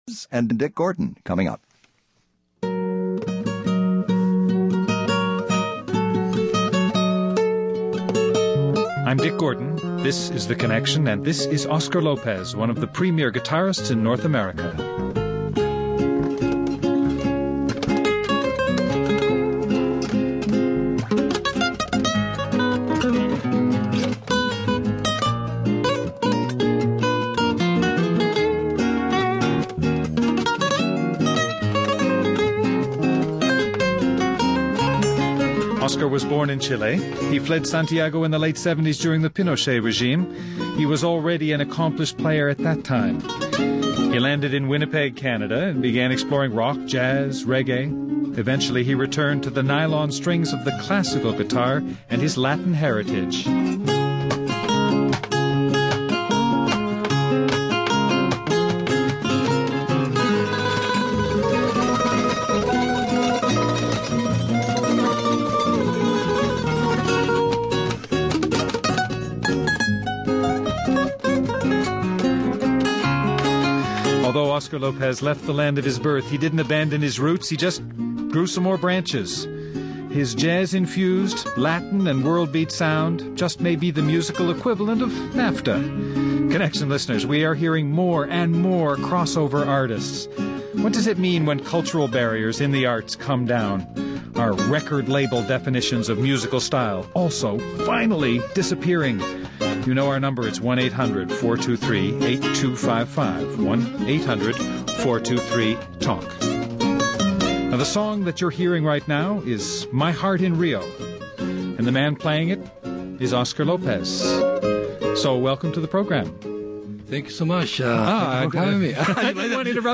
The Chilean exile’s passion for music has led him to all corners of the musical globe, but in the end he’s returned to the nylon-stringed wooden box he calls home.